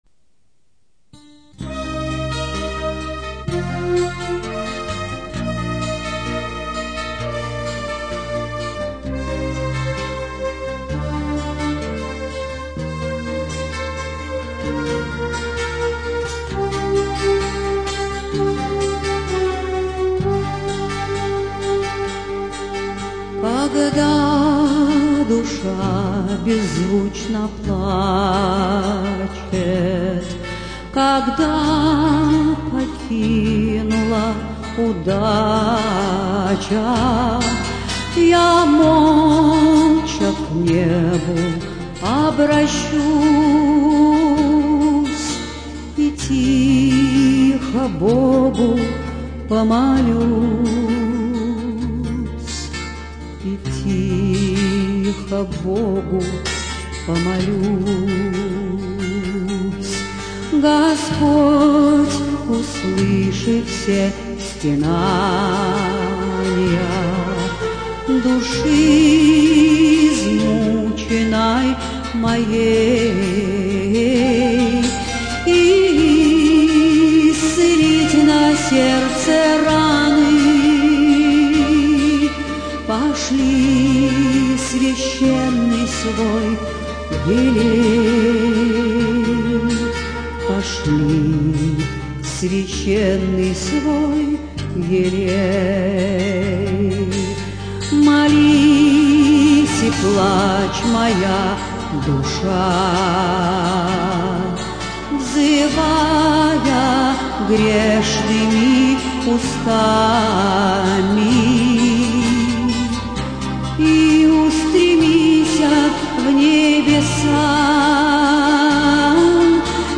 Православная музыка